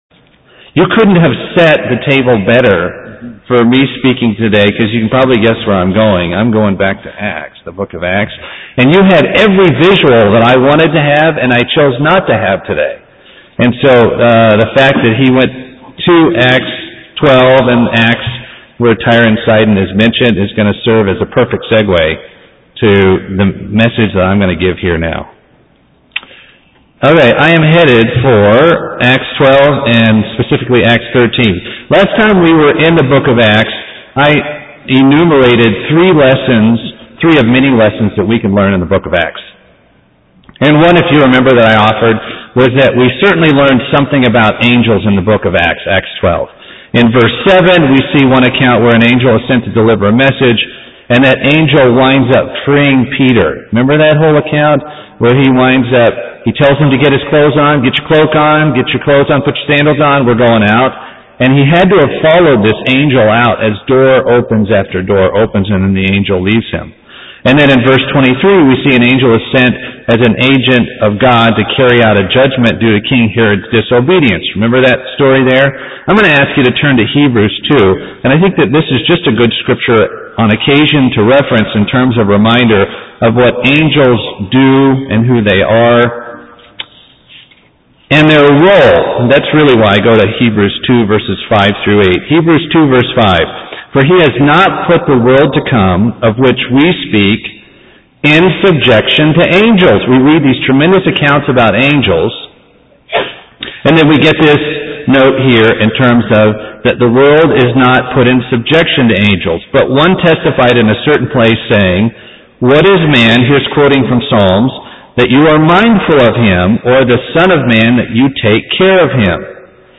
An ongoing Bible study on the books of Acts. Paul's sermons in Acts 13 are discussed.